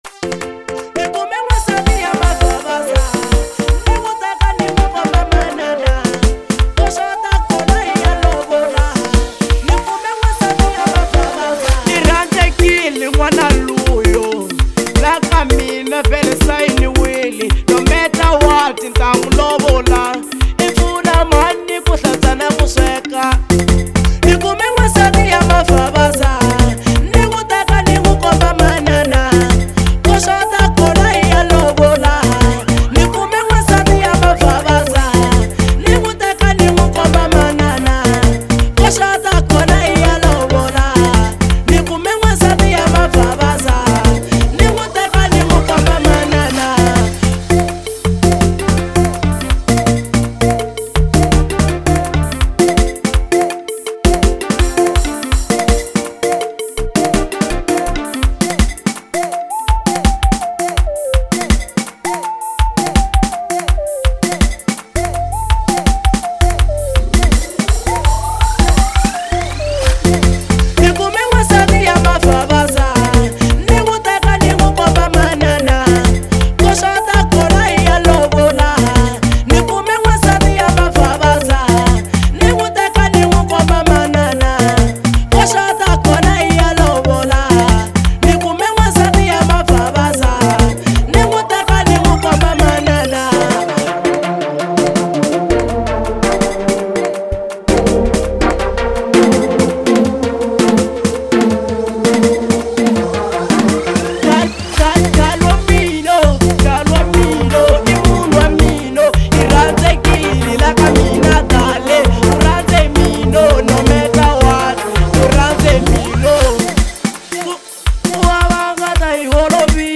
03:10 Genre : Xitsonga Size